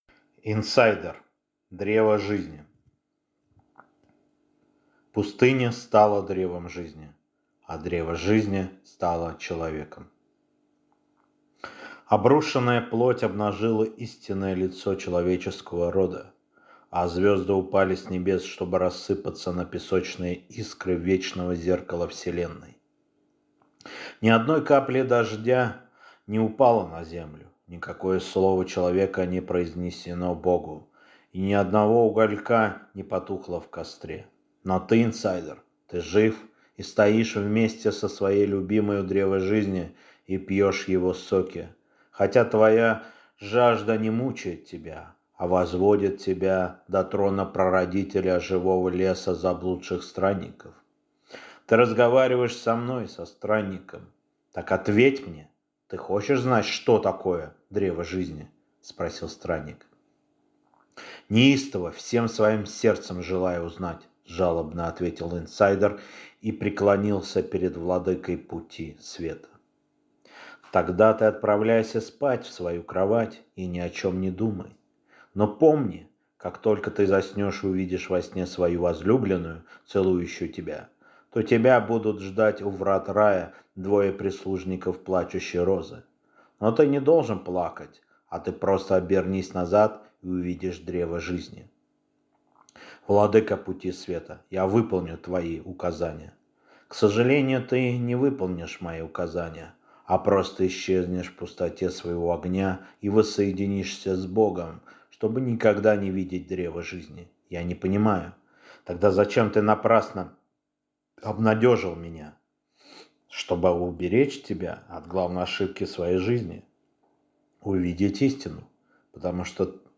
Аудиокнига Инсайдер. Древо Жизни | Библиотека аудиокниг